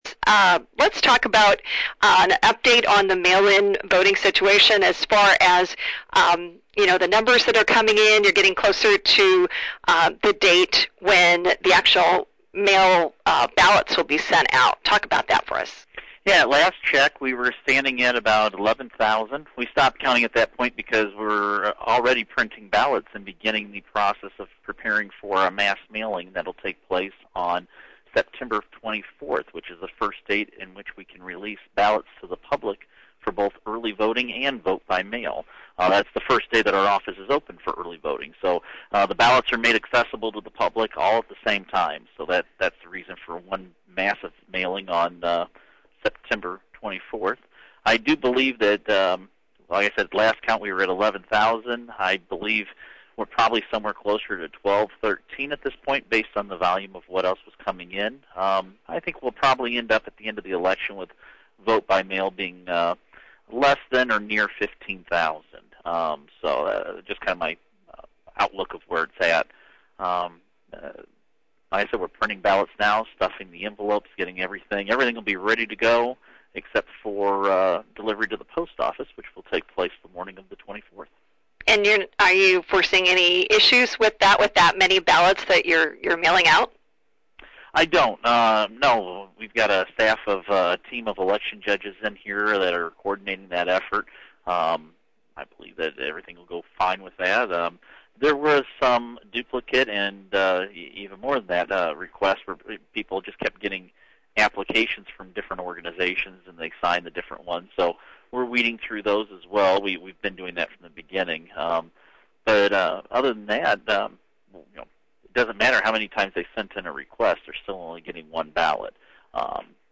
Here’s the full interview with Ackerman.